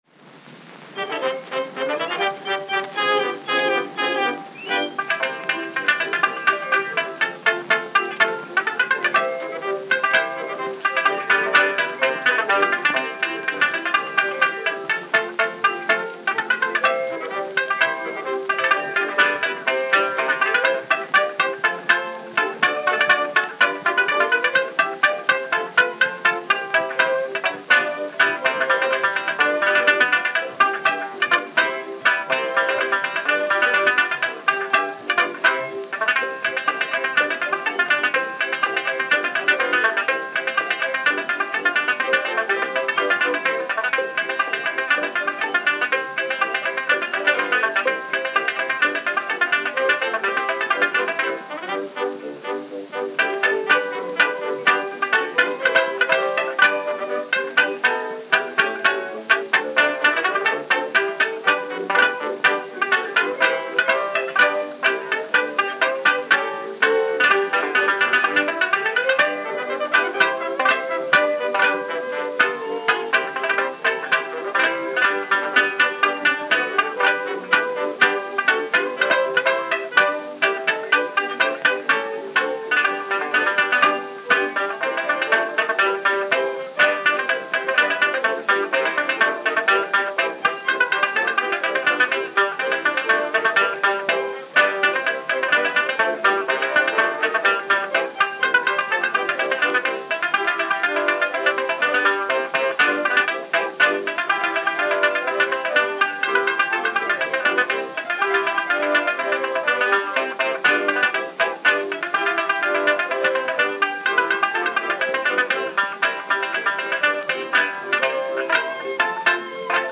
Edison Diamond Discs